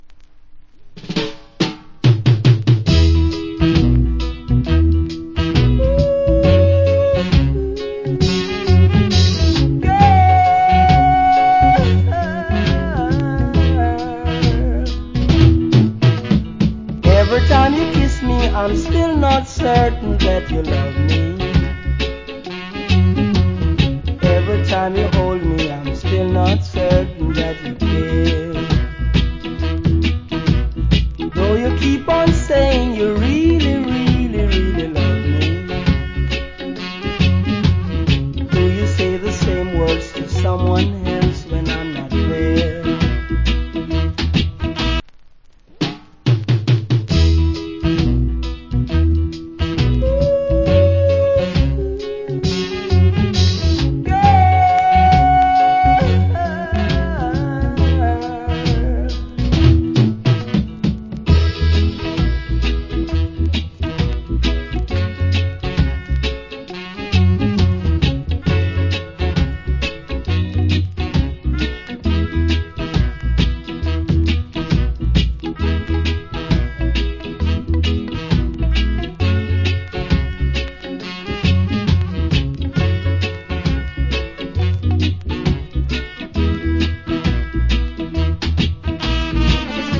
Good Reggae Vocal.